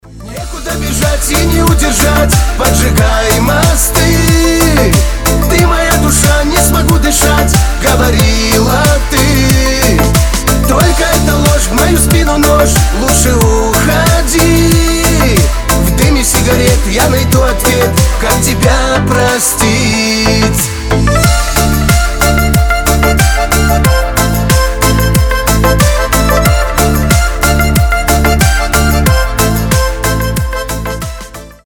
• Качество: 320, Stereo
кавказские